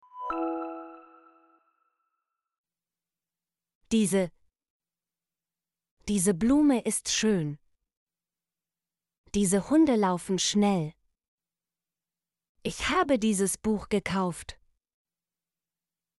diese - Example Sentences & Pronunciation, German Frequency List